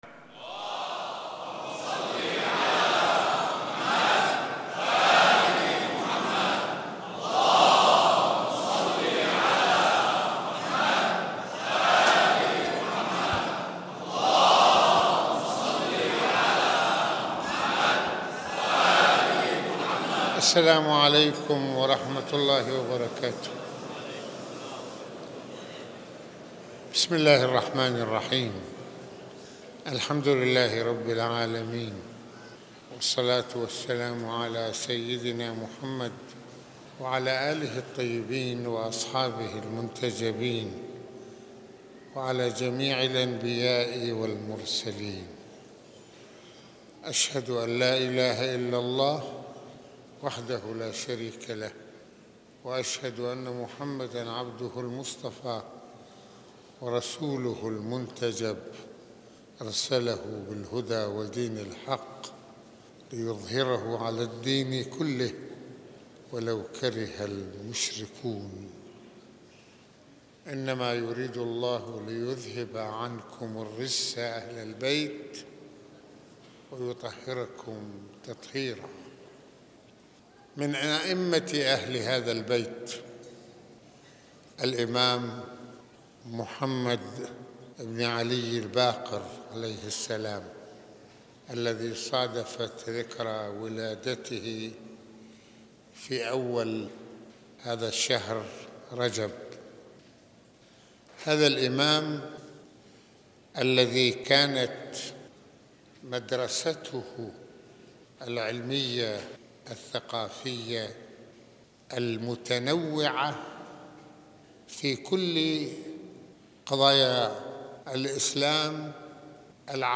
خطبة الجمعة المكان : مسجد الإمامين الحسنين (ع)